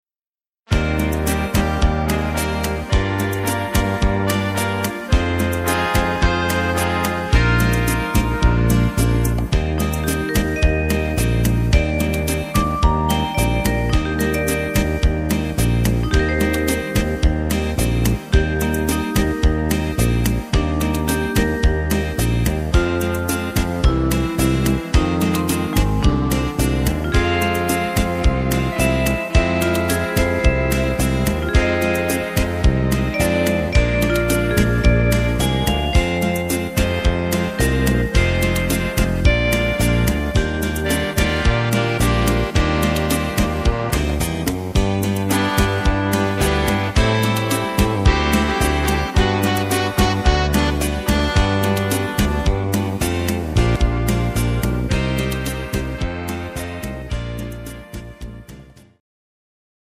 instr. Klarinette